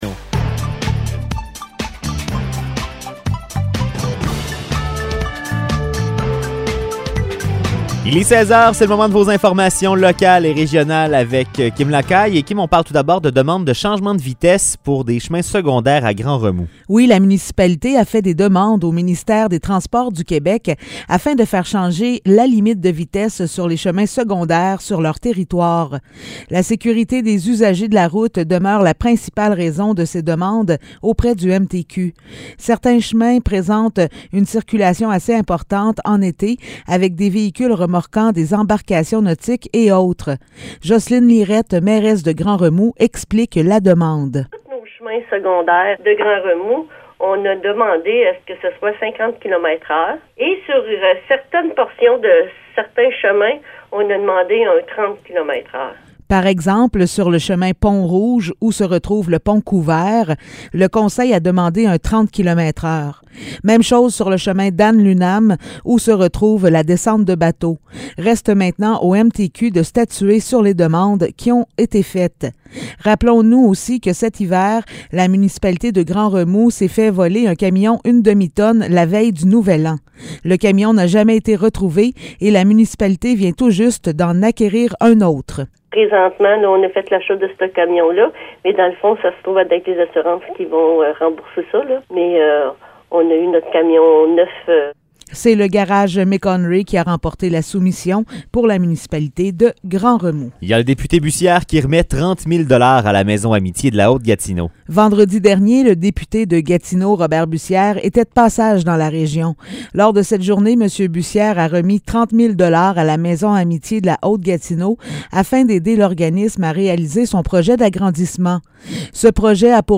Nouvelles locales - 28 mars 2022 - 16 h